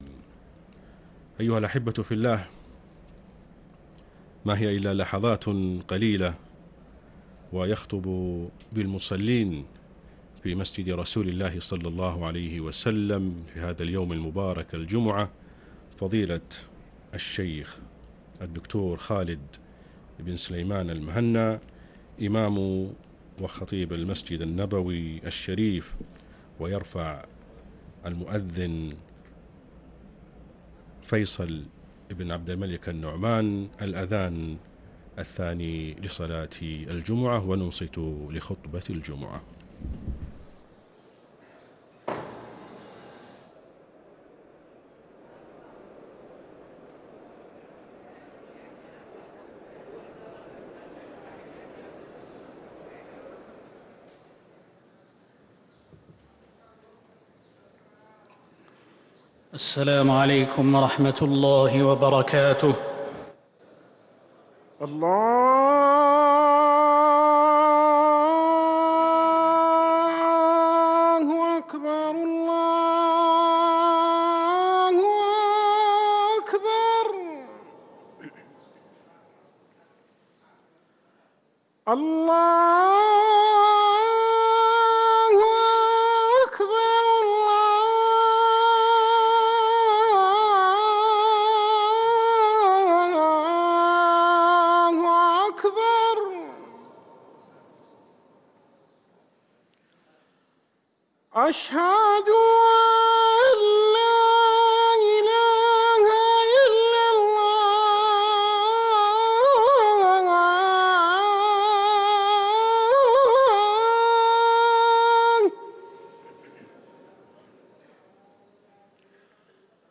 ركن الأذان 🕌